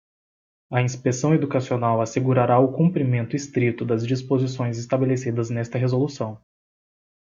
/kũ.pɾiˈmẽ.tu/